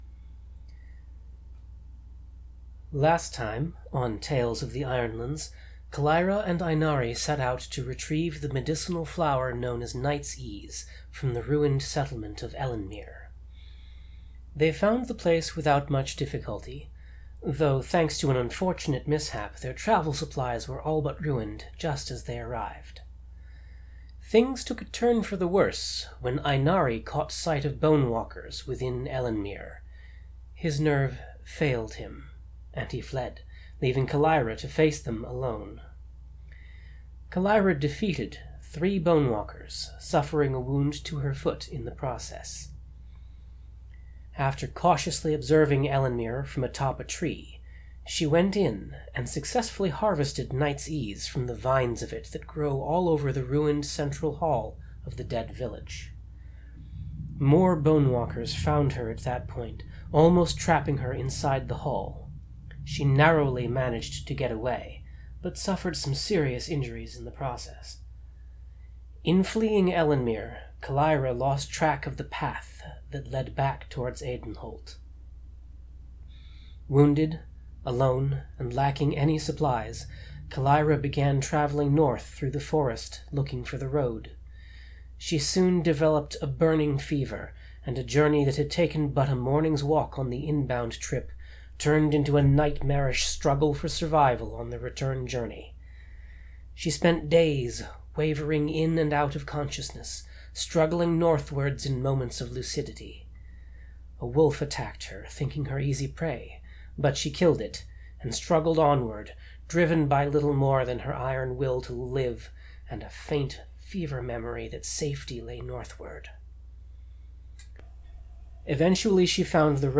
Note: This has been edited to remove uninteresting bits such as silence, throat-clearing and paper shuffling.